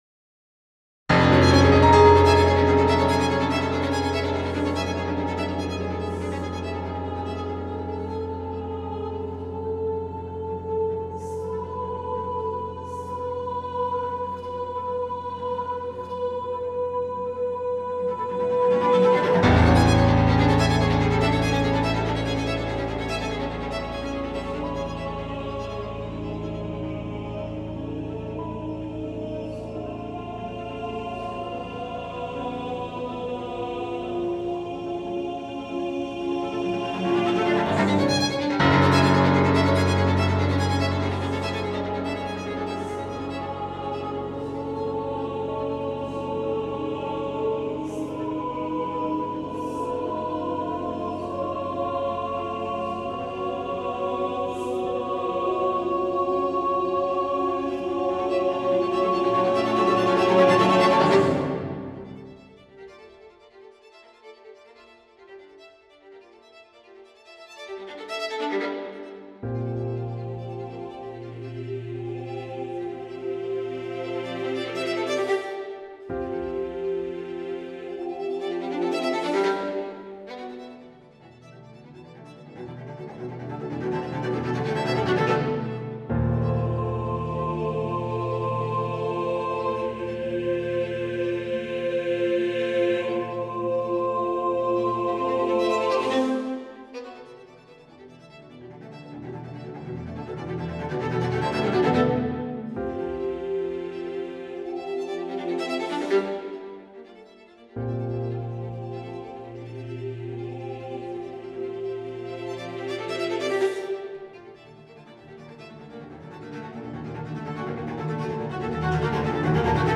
a choral and chamber music composition in nine movements